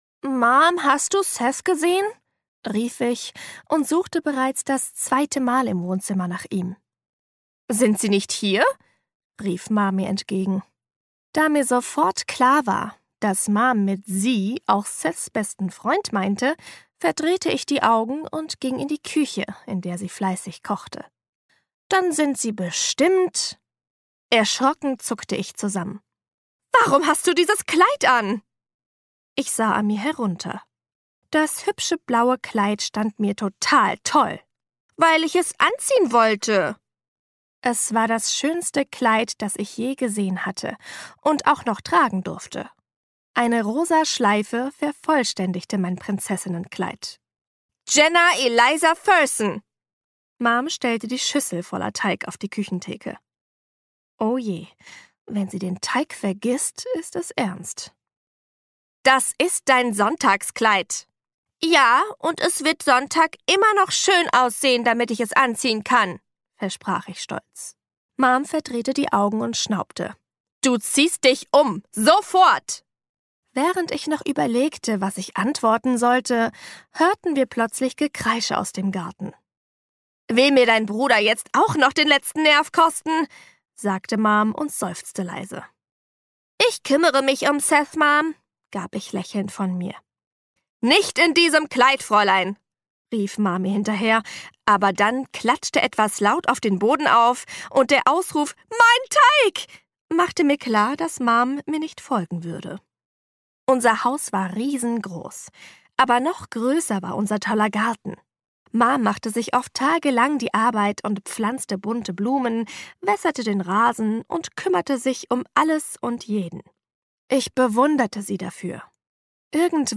Hörbuch – Who wins, Jenna?
warm mezzo weiblich jung empathisch dynamisch